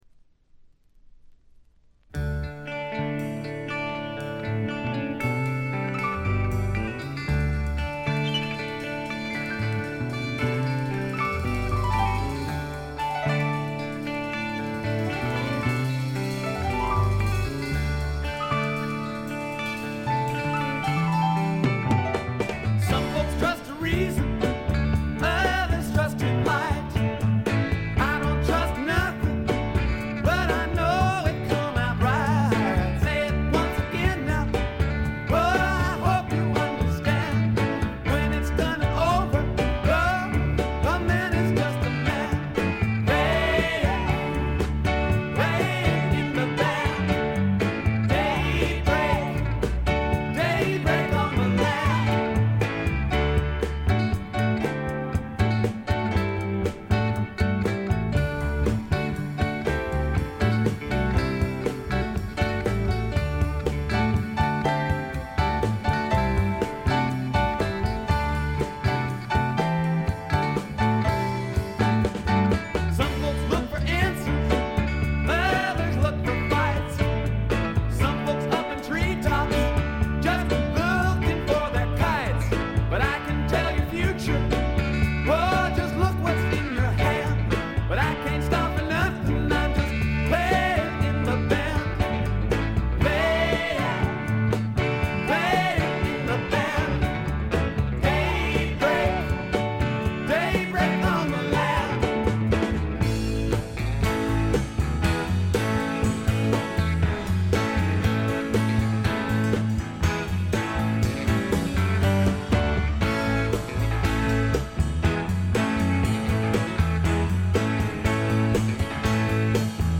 ほとんどノイズ感無し。
試聴曲は現品からの取り込み音源です。
lead vocals, electric and acoustic guitars
piano, organ
drums, percussion